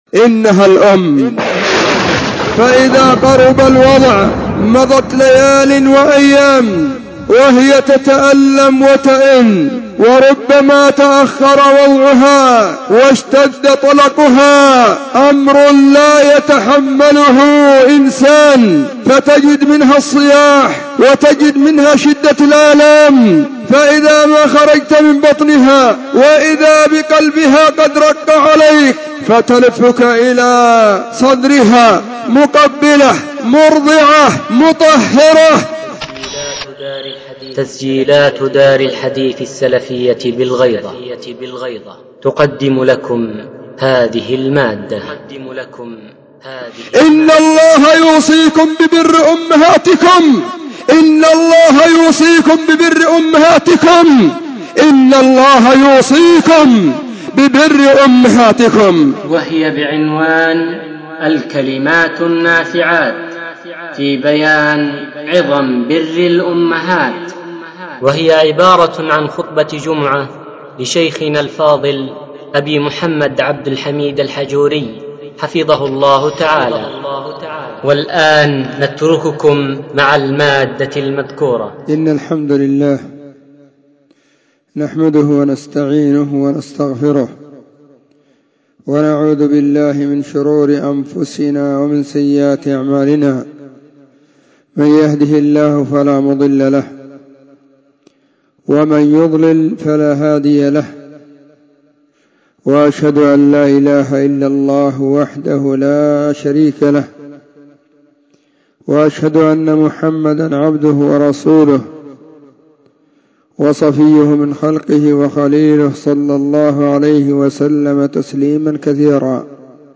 خطبة جمعة بعنوان :الكلمات النافعات في بينان عظم بر الأمهات
📢 وكانت في مسجد الصحابة بالغيضة محافظة المهرة – اليمن.